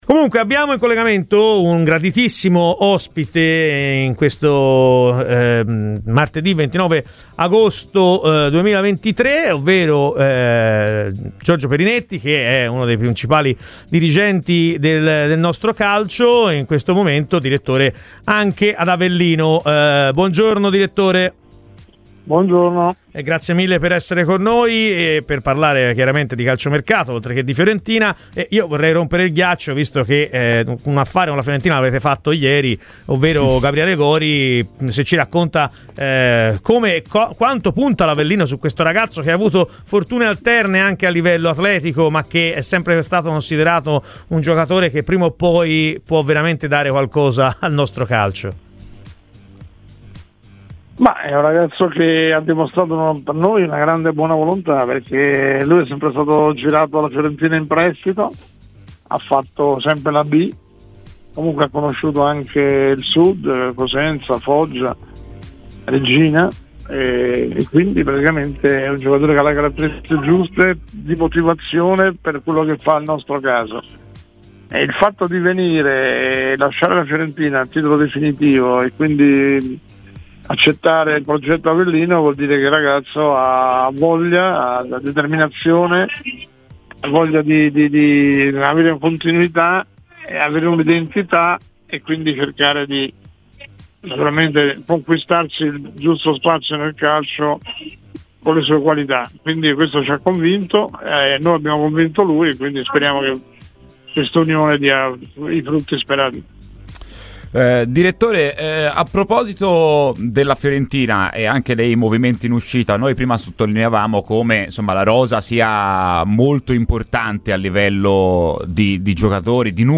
ha parlato a Radio FirenzeViola durante la trasmissione Chi si Compra:.